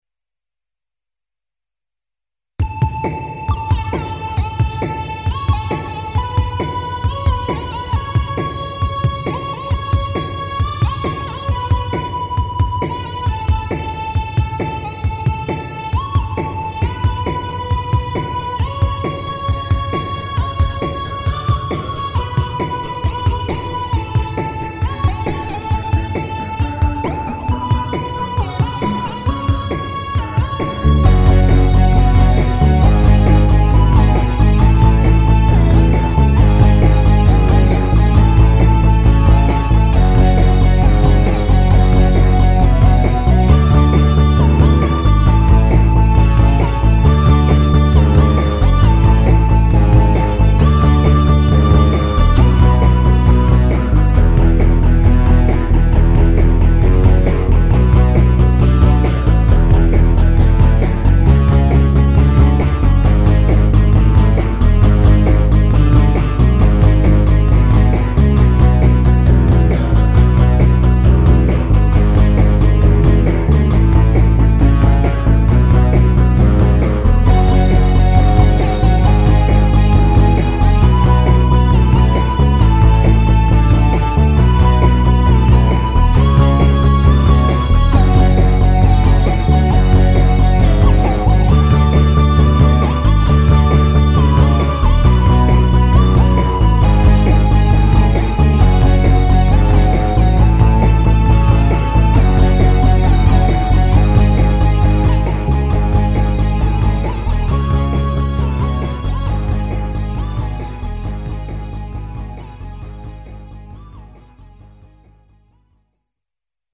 Hopalong (1.50) Mi (E) -  135
Plugins :  Font12! , Evm Bass Line , MDA EPiano , Synth1 ,
Drum loop : Arythm
Mode : Mixolydien